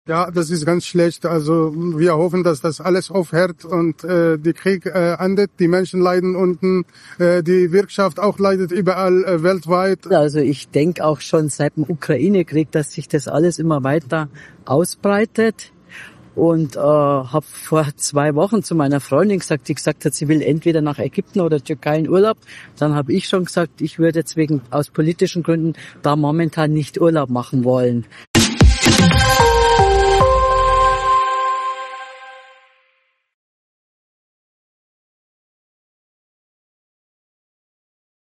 hat sich im Berliner Stadtteil Neukölln umgehört.